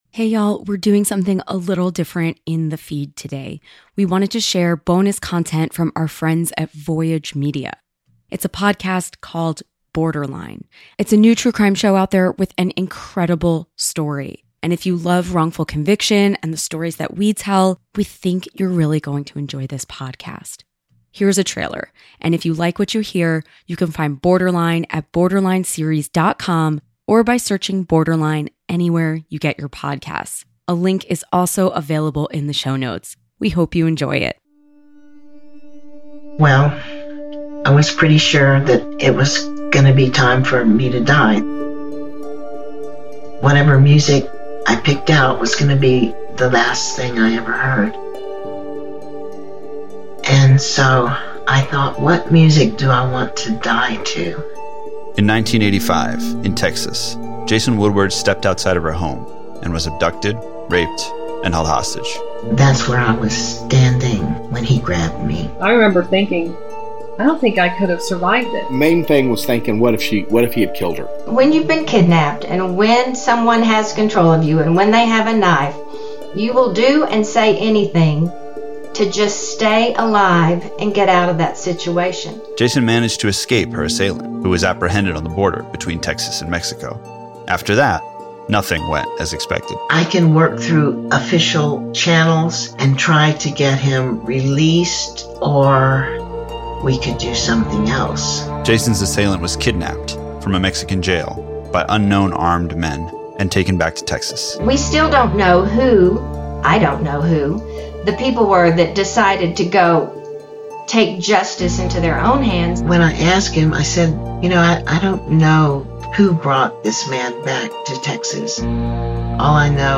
Today we want to tell you about a new show from our friends at Voyage Media called Borderline. Hosted by Paget Brewster (Criminal Minds), it’s a true crime show with an incredible story that we think you will like. Here’s a trailer.